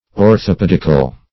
Orthopedic \Or`tho*ped"ic\, Orthopedical \Or`tho*ped"ic*al\, a.